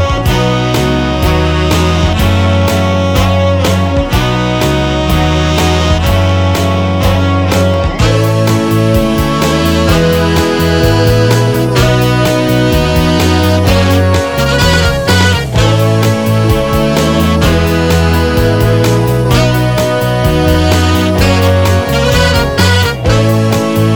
no Backing Vocals Pop (1980s) 4:00 Buy £1.50